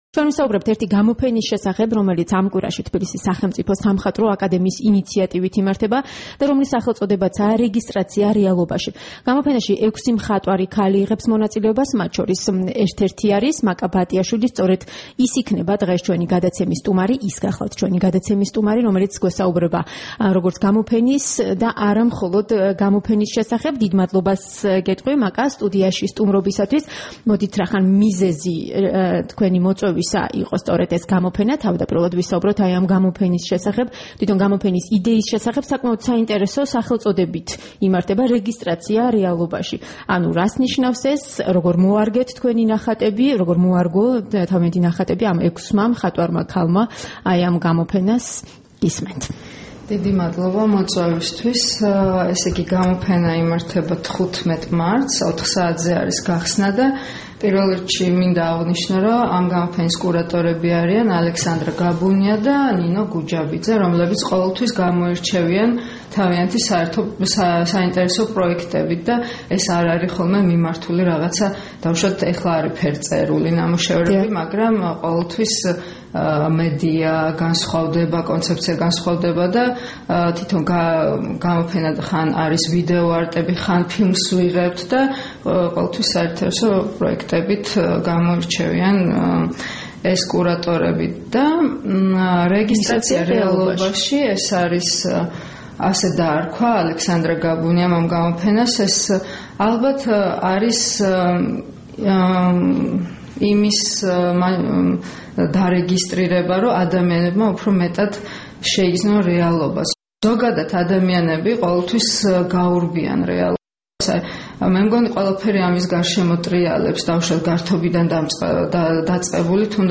რადიო თავისუფლების დილის გადაცემის სტუმარი
საუბარი